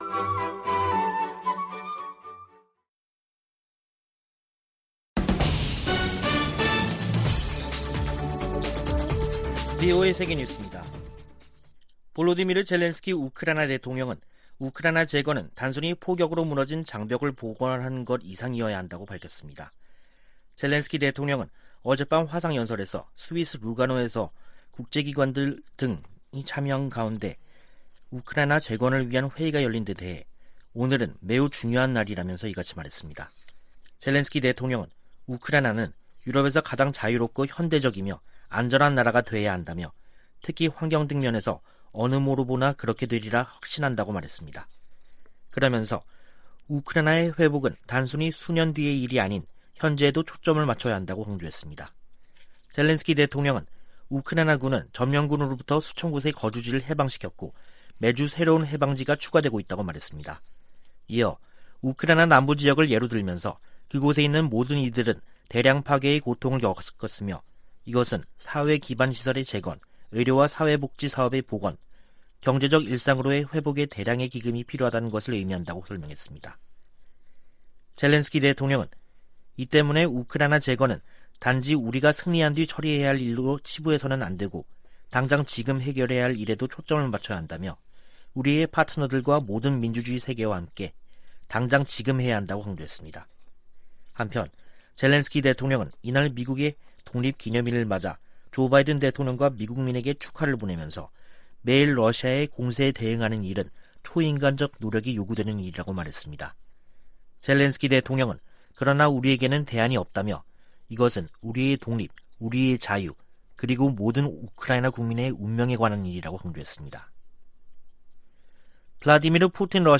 생방송 여기는 워싱턴입니다 2022/7/5 저녁
세계 뉴스와 함께 미국의 모든 것을 소개하는 '생방송 여기는 워싱턴입니다', 2022년 7월 5일 저녁 방송입니다.